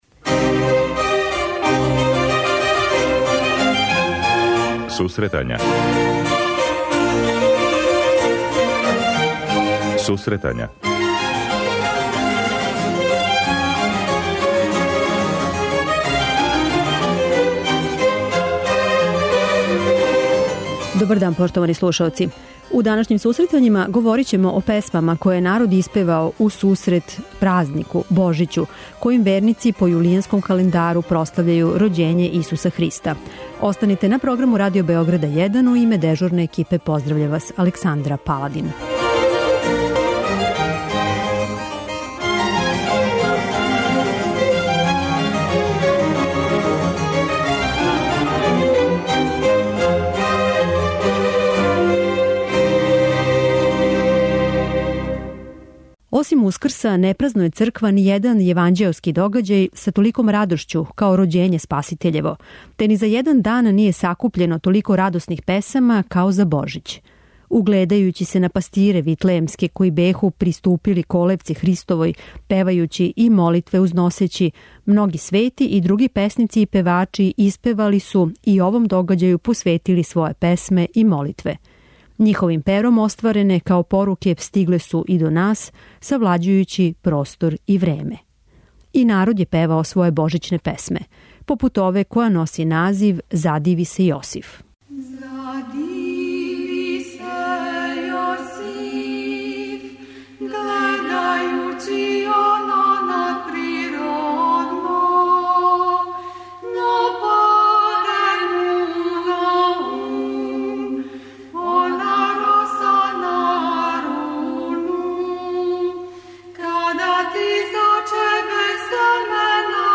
У сусрет великом хришћанском празнику Божићу, који се по јулијанском календару прославља 7. јануара, у данашњој емисији представићемо песме и духовне молитве које се превају у сусрет празнику.